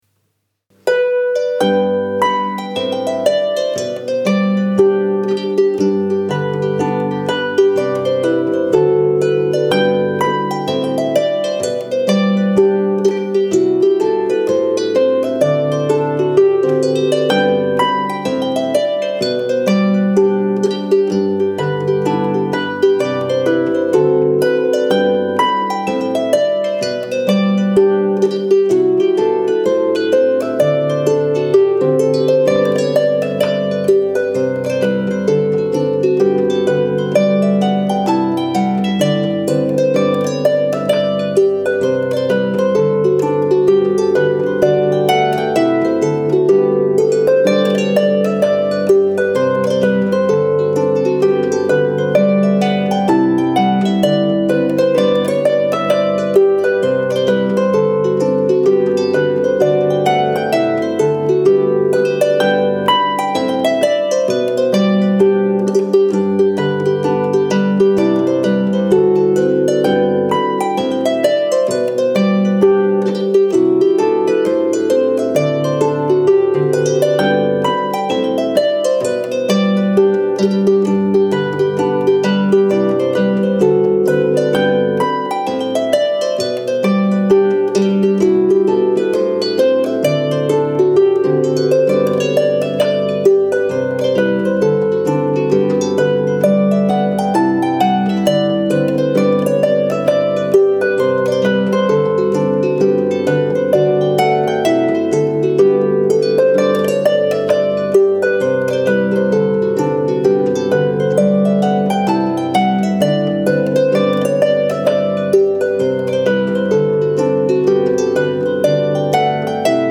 ADVANCED HARP TECHNIQUE
Quick Lever Changes
G Major
Hornpipe